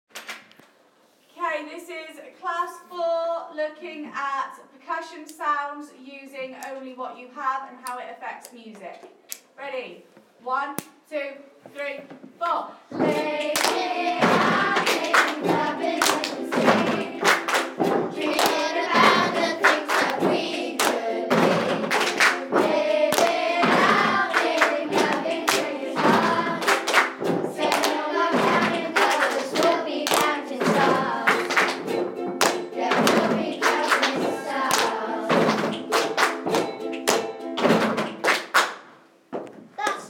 D4 Percussion